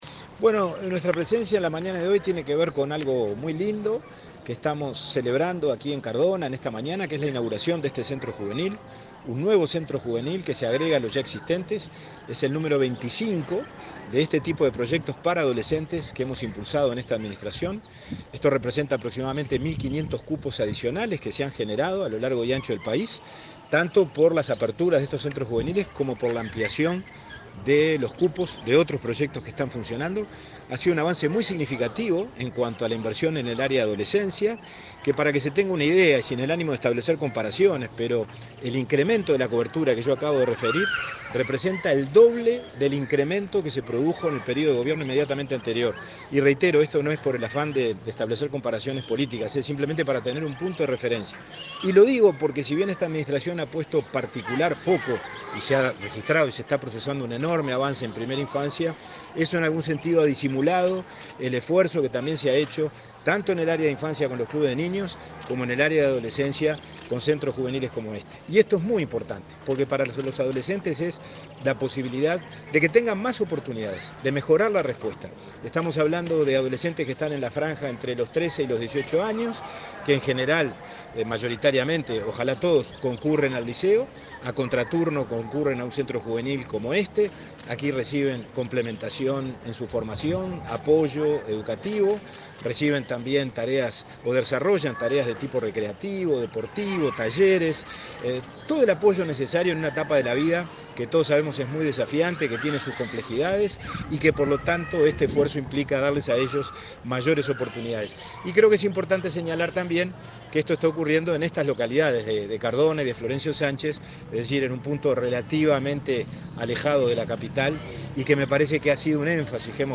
Entrevista al presidente del INAU, Pablo Abdala
El presidente del Instituto del Niño y el Adolescente del Uruguay (INAU), Pablo Abdala, dialogó con Comunicación Presidencial, durante la inauguración